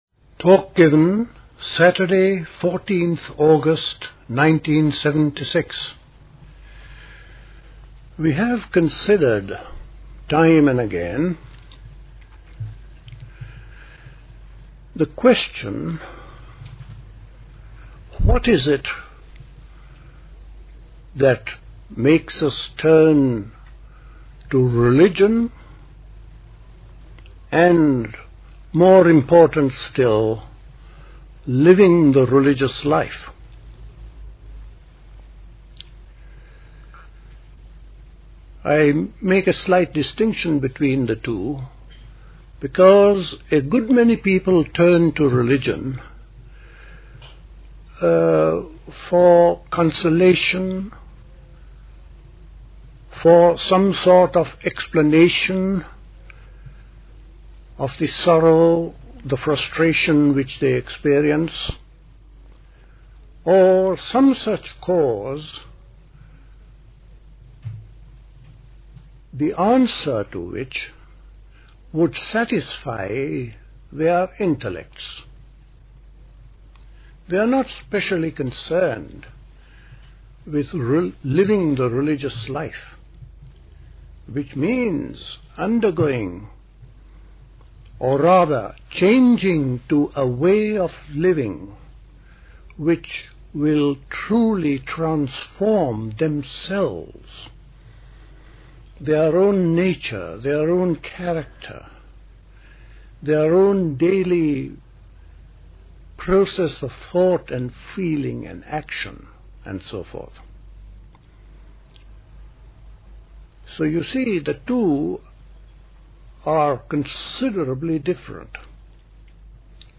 A talk
at Dilkusha, Forest Hill, London on 14th August 1976